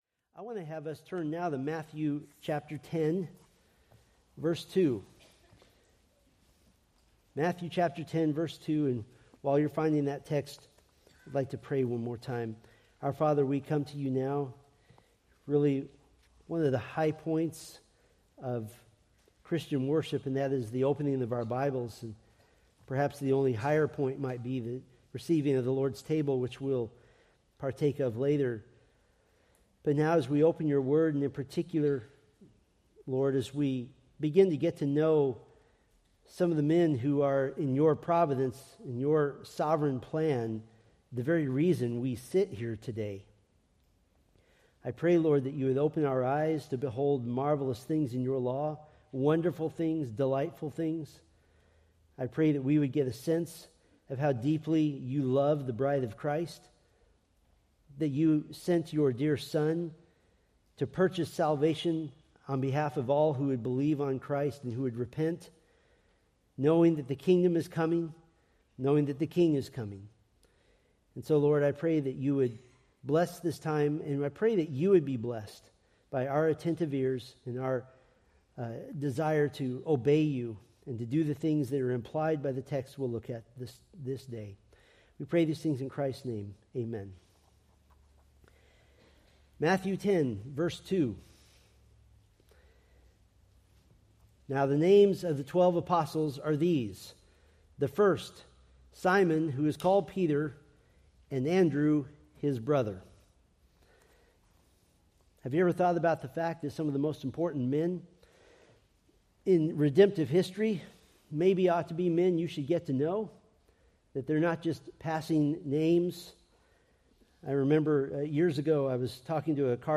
Preached February 9, 2025 from Matthew 10:2